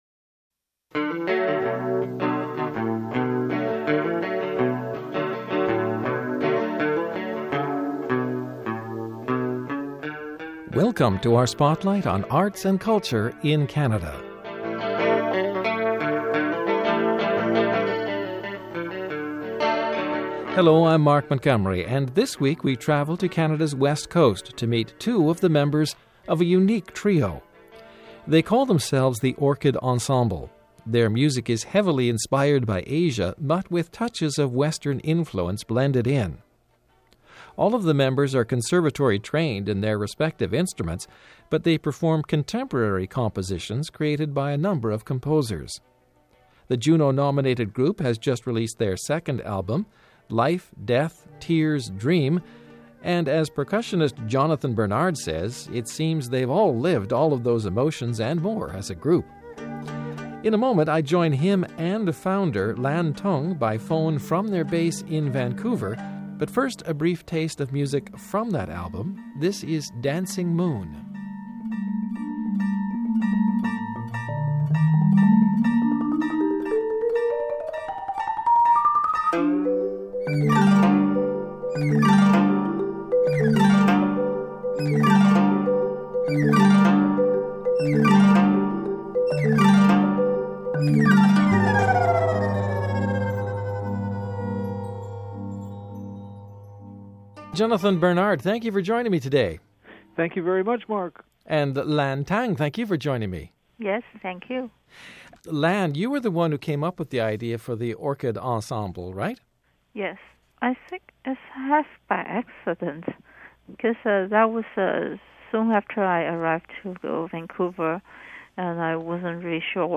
Their music is heavily inspired by Asia, but with touches of western influence blended in.